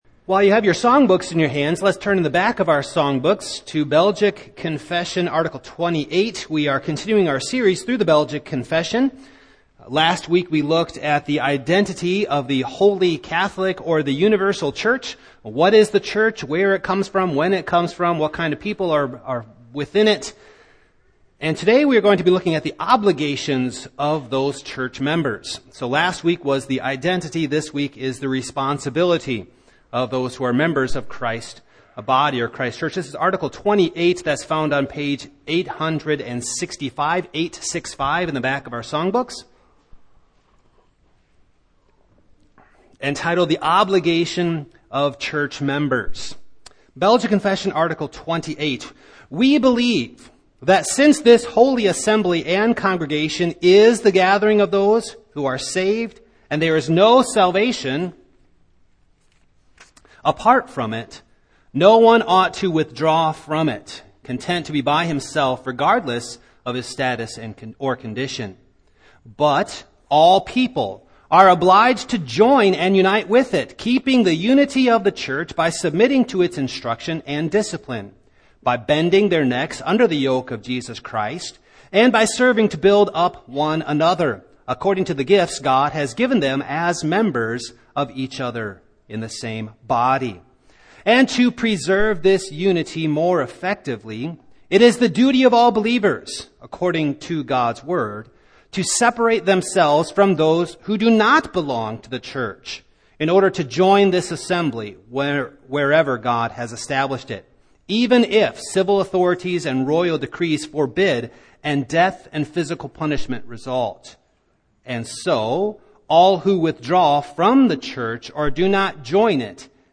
Passage: Heb. 10:19-31 Service Type: Morning